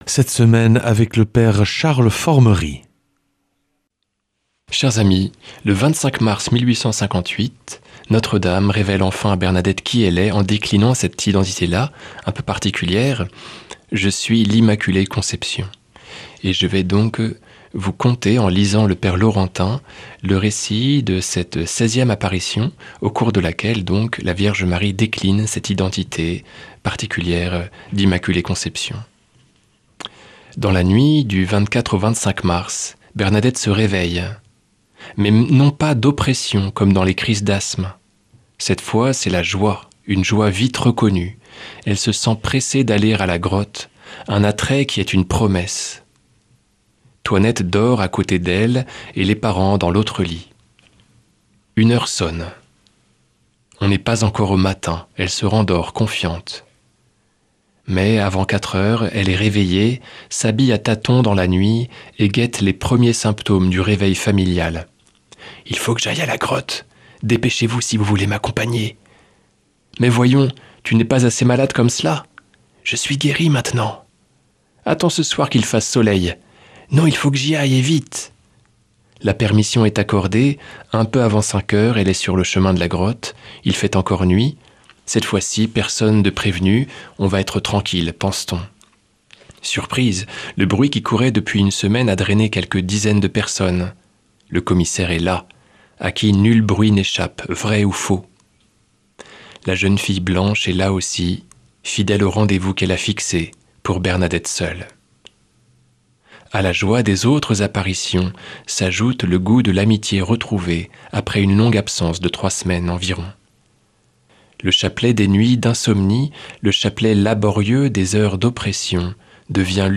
jeudi 27 novembre 2025 Enseignement Marial Durée 10 min